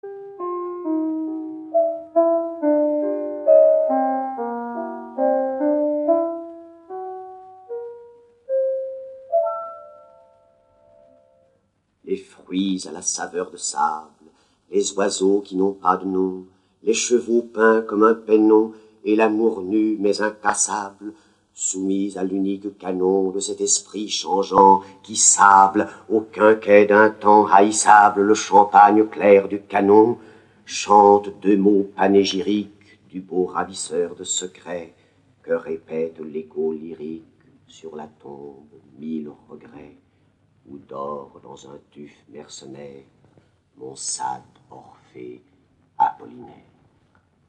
0% Extrait gratuit Poésie de Louis Aragon Éditeur : Compagnie du Savoir Paru le : 2010 Résumé Les 8 plus beaux poèmes de Louis Aragon dits par Jean-Louis Barrault : Un air embaumé, Poèmes de cape et d'épée, Les amants séparés, Richard II Quarante, Il n'y a pas d'amour heureux, Le paysan de Paris chante, Amour d'Elsa, Le cri du Butor.